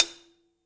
Cymbol Shard 10.wav